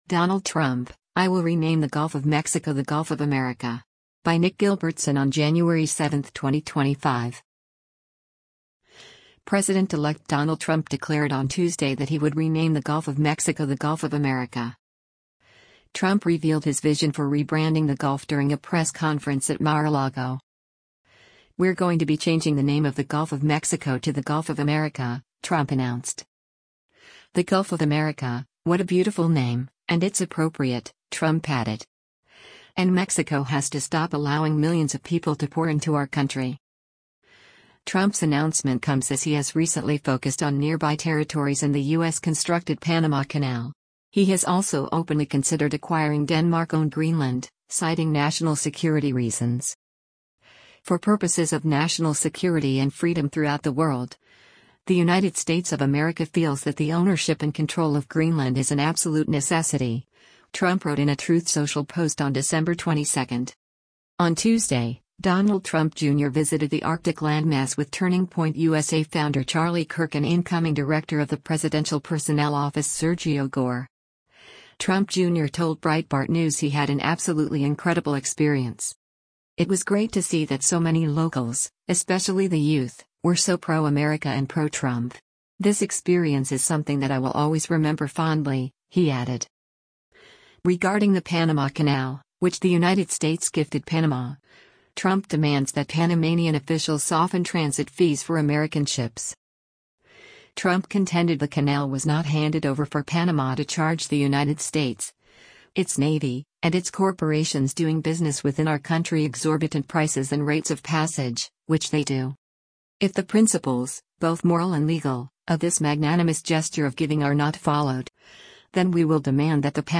Trump revealed his vision for rebranding the gulf during a press conference at Mar-a-Lago.